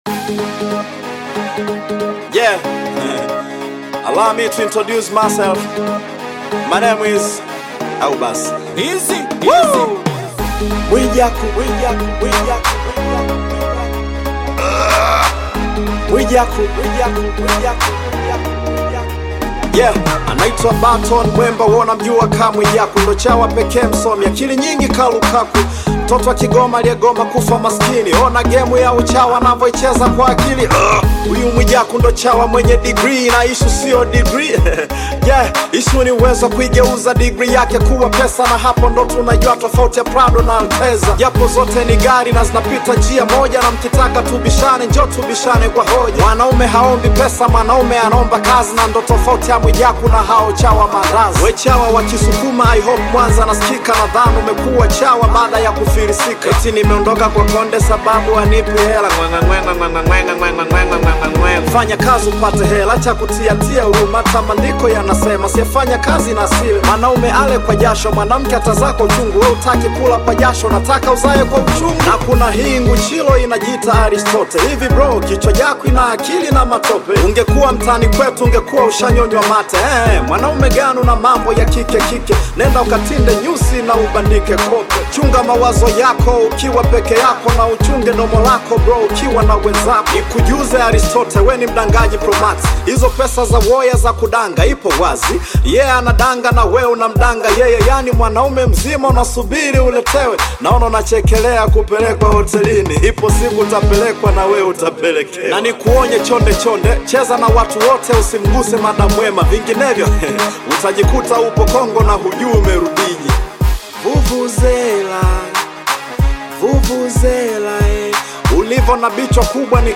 Upcoming rapper songwriter
Hip Hop song